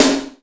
SNARE 007.wav